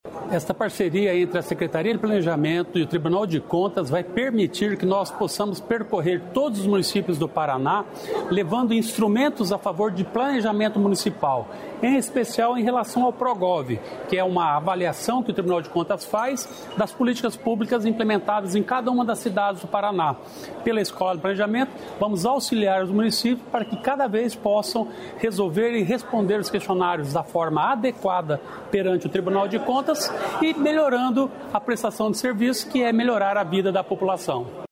Sonora do secretário do Planejamento, Ulisses Maia, sobre parceria para fortalecer a gestão pública municipal